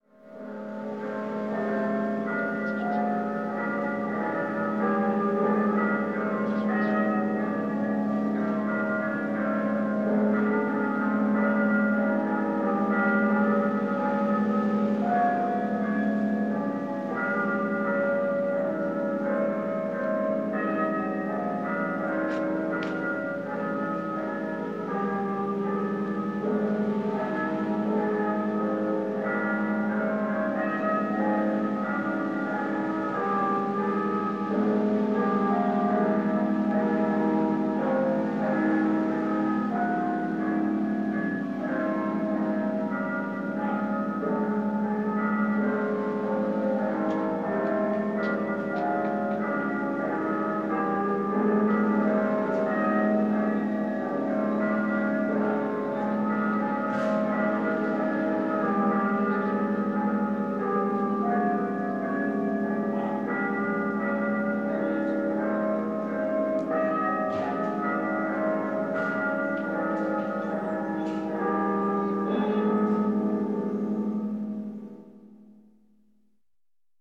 Peaceful Bell